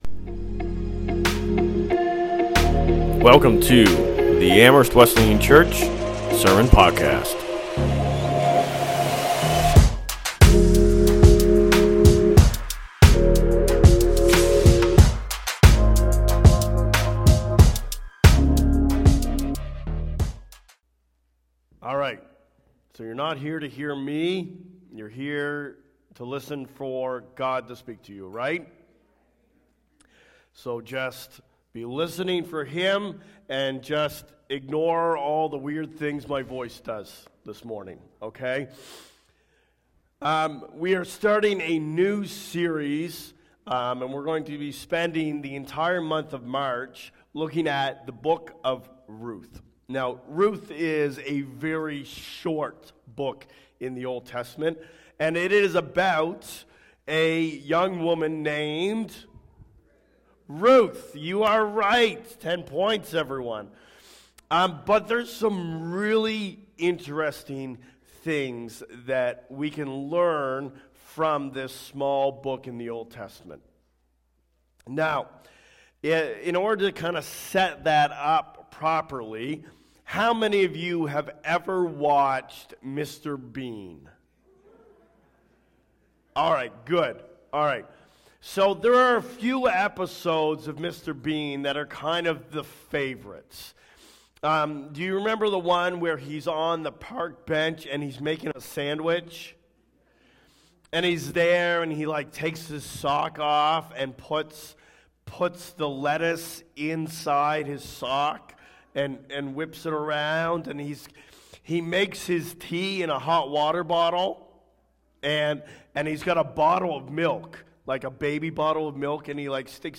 2026 Current Sermon Your Way Your way is not the right way.